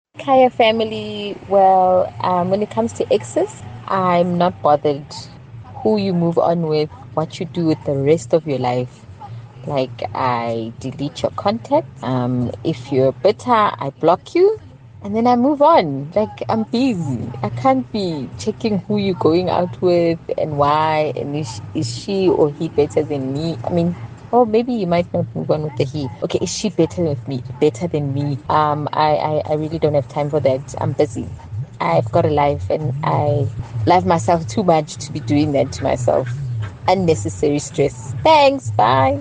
Here’s how Kaya Drive listeners felt about comparisons with their ex’s new partner: